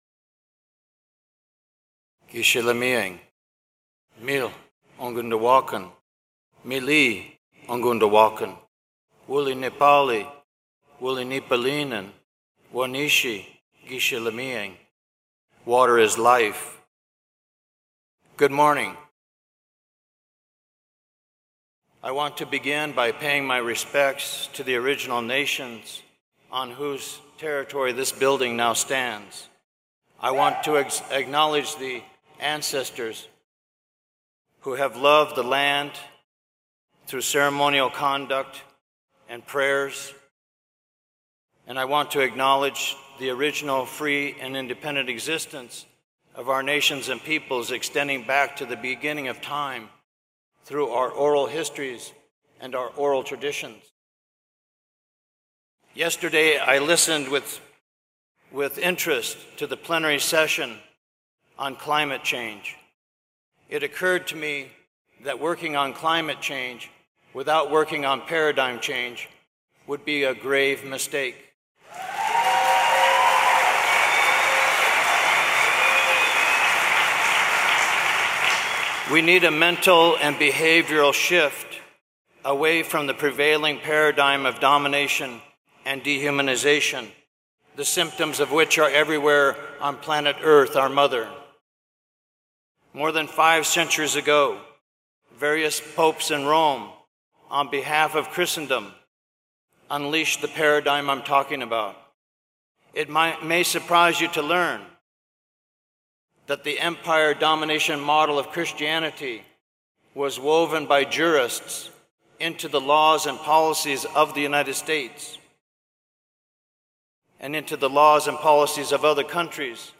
Spotlight of Indigenous Peoples Plenary, 8,000 People Attending
2015 Parliament of the World’s Religions, 19 Oct 2015